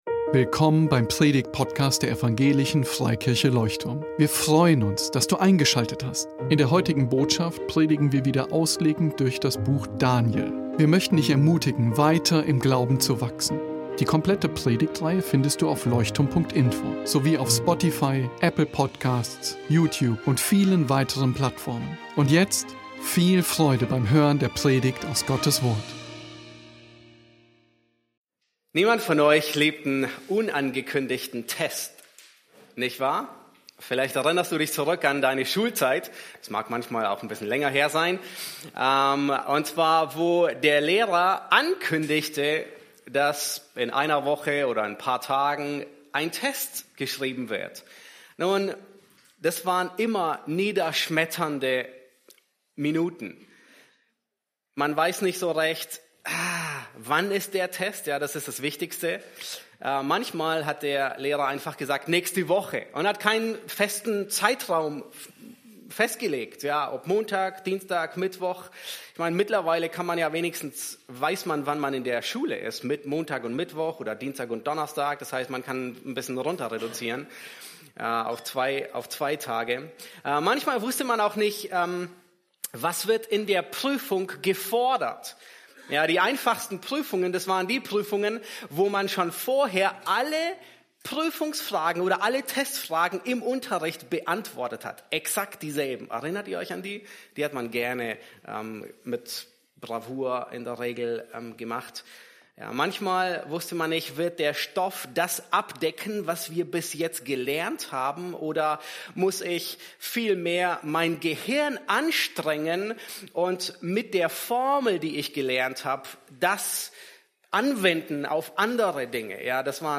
Daniel 8 | Gottes Vorbereitung auf Prüfungen ~ Leuchtturm Predigtpodcast Podcast